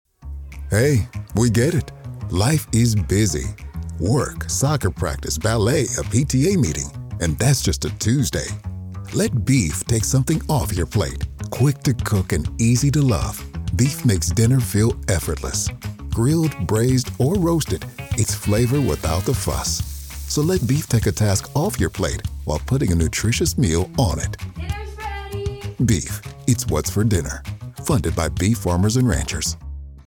National Radio Ads